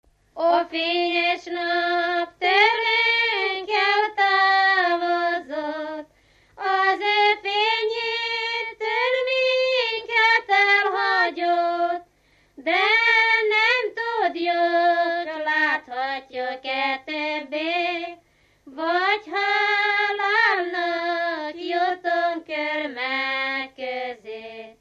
Dunántúl - Verőce vm. - Lacháza
ének
Műfaj: Népének
Stílus: 9. Emelkedő nagyambitusú dallamok